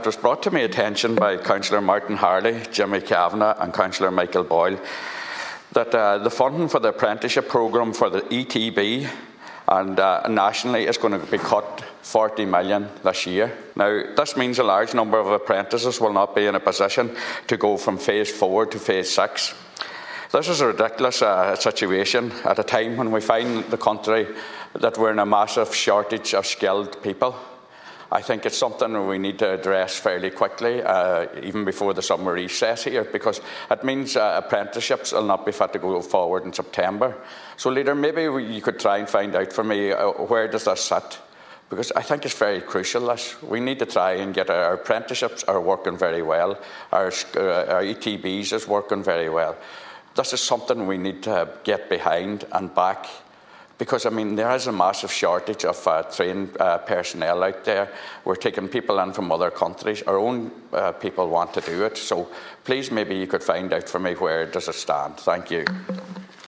Speaking in the Seanad earlier this week, Senator Manus Boyle says, due to this, the Education Training Board will be forced to cancel and delay scheduled training.